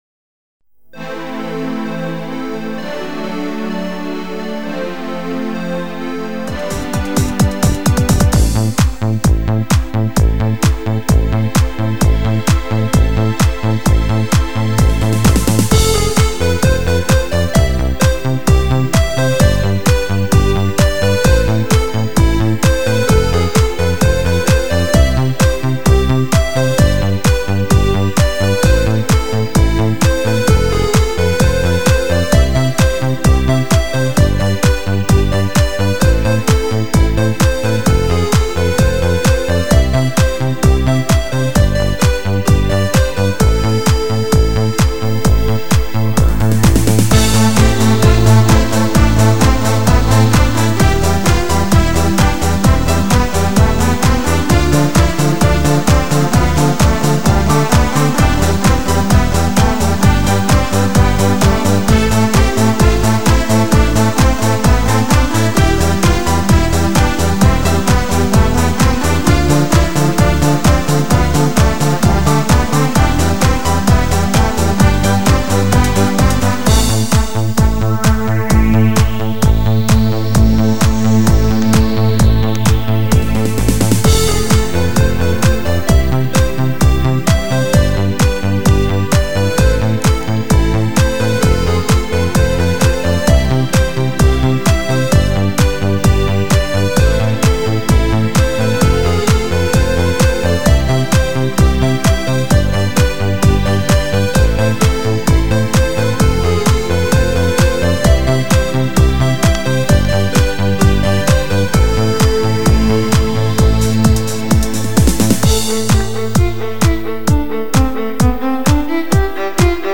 Canzoni e musiche da ballo
ballo di gruppo